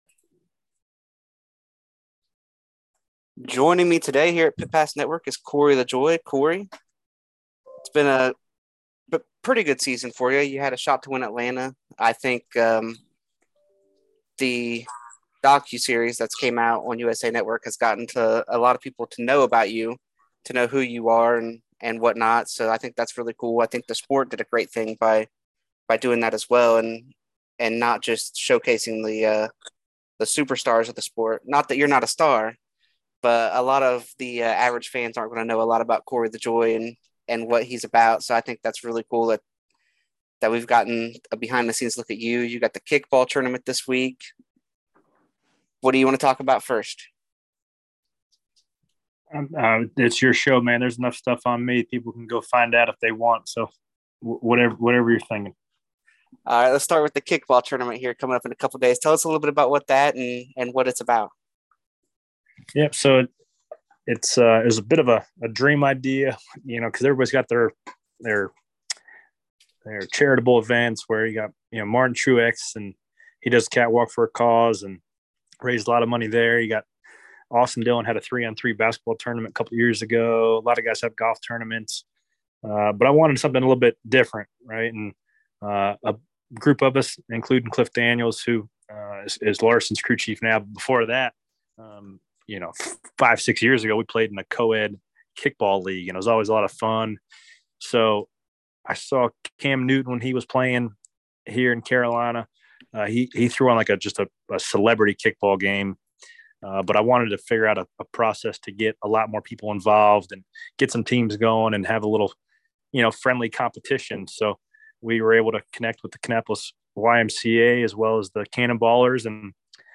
Pit Pass Network Interview: Corey LaJoie